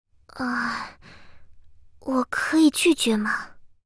贡献 ） 协议：Copyright，人物： 碧蓝航线:应瑞语音 2022年5月27日